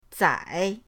zai3.mp3